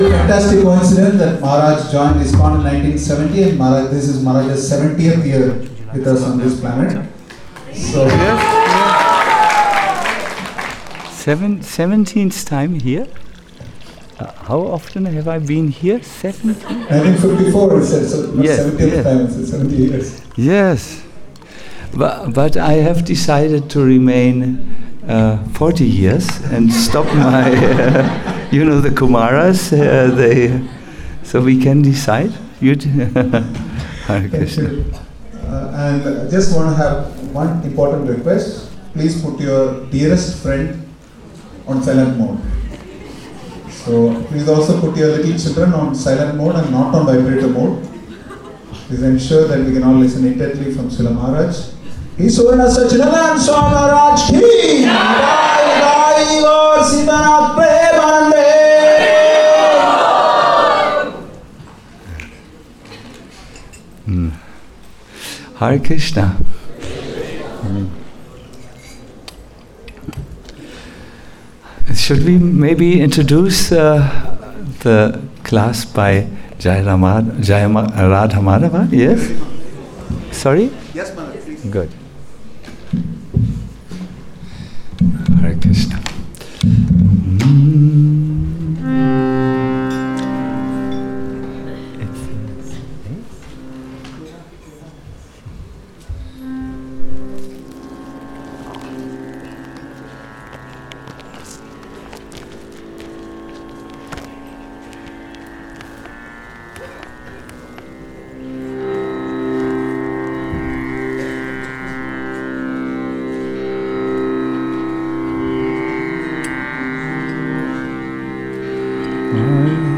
Lectures and Seminars